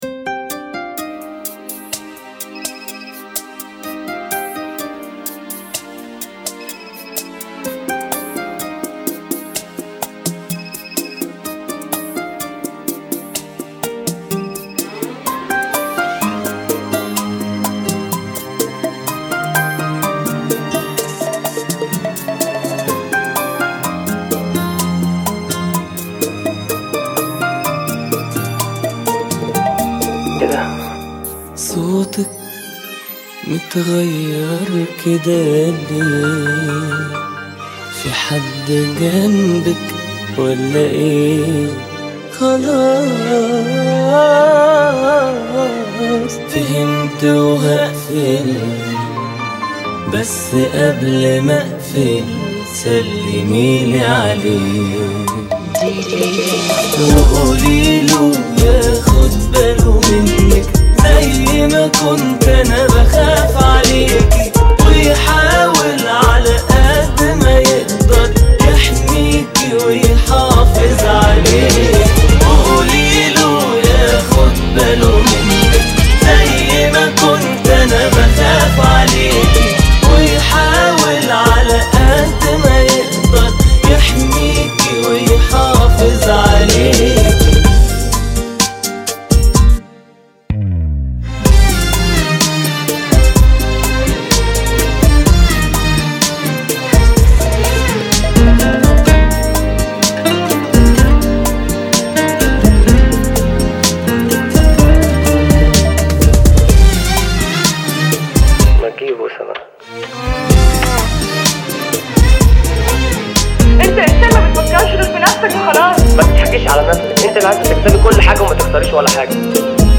126 BPM
Genre: Bachata Remix